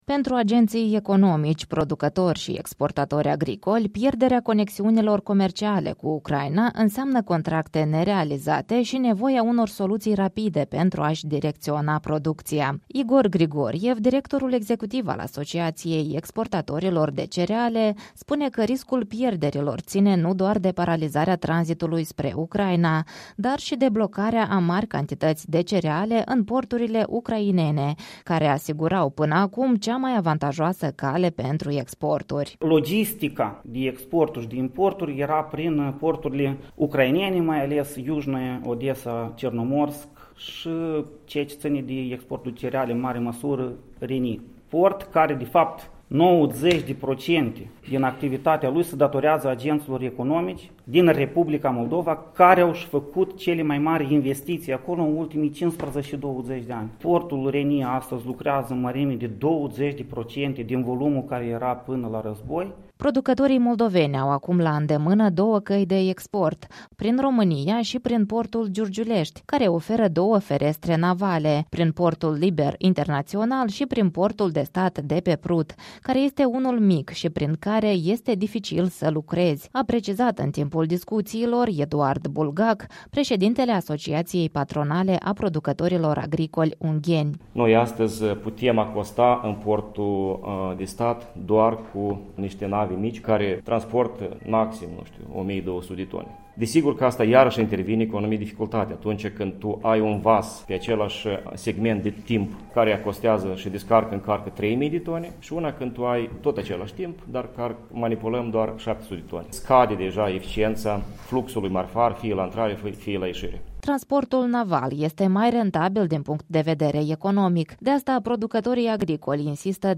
Războiul din Ucraina schimbă radical direcția exporturilor de produse agricole, afectate fiind și lanțurile logistice de import din spațiul CSI a îngrășămintelor minerale pe care se miza în proporție de peste 80 la sută. Agricultorii moldoveni sunt puși în situația să tranziteze România, numai că până acum această cale nu a fost niciodată prioritară pentru exportul de cereale, dar pentru alte produse agricole, au atras atenția mai mulți reprezentați ai sectorului, prezenți la o dezbatere organizată de Institutul Viitorul.